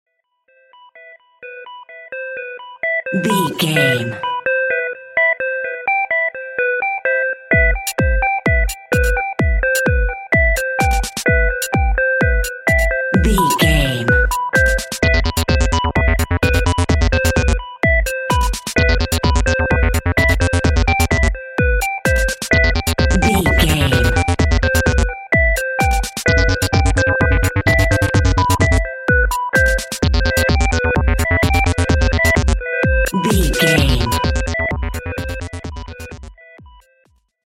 Aeolian/Minor
groovy
dreamy
tranquil
meditative
futuristic
industrial
electronic
techno
trance
drone
glitch
synth lead
synth bass
synth drums